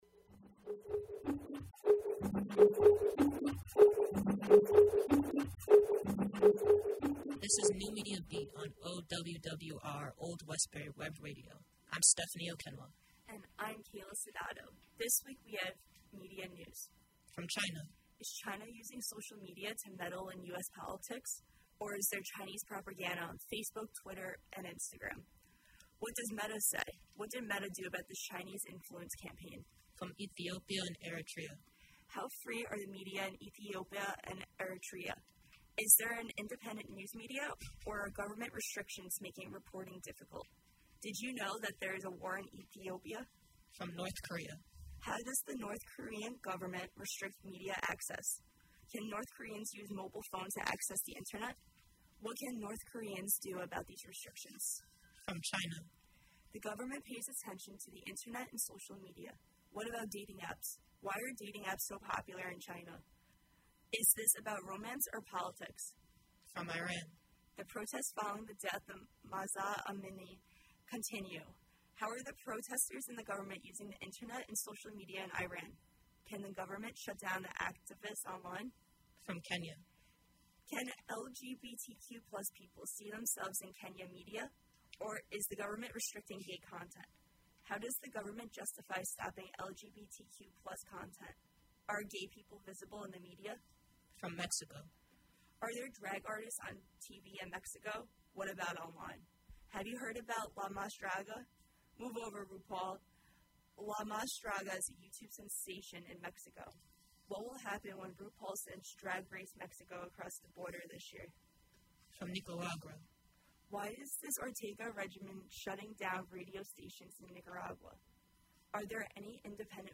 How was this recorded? The NMB Podcast streams live on Old Westbury Web Radio Thursdays from 10:00-11:00 AM EST. Can’t listen live?